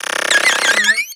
Cri de Rapion dans Pokémon X et Y.